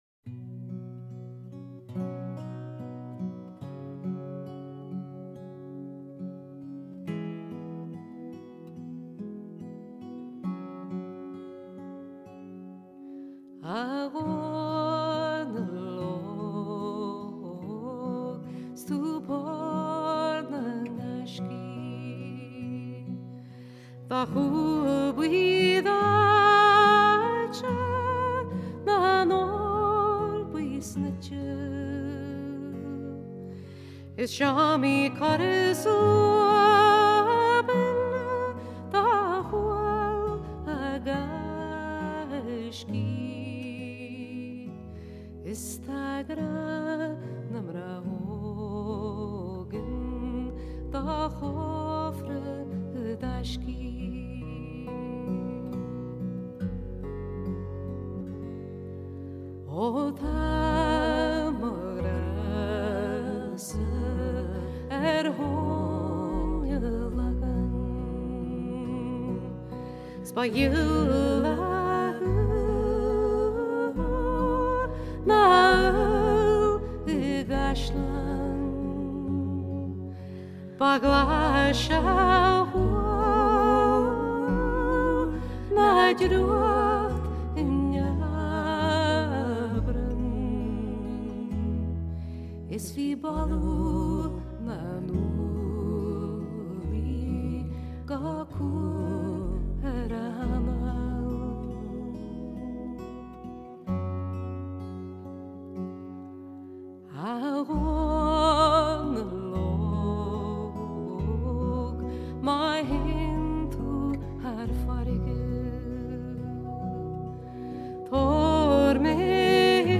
Vocals, Keyboards
Bouzouki, Bodhrán, Guitar, Keyboards, Synthesizer
Harp
Fiddle
Accordion
Whistle
Percussion